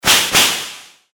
風 02二連
/ F｜演出・アニメ・心理 / F-30 ｜Magic 魔法・特殊効果
ピシィーピシィー